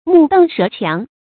目瞪舌強 注音： ㄇㄨˋ ㄉㄥˋ ㄕㄜˊ ㄑㄧㄤˊ 讀音讀法： 意思解釋： 同「目瞪口呆」。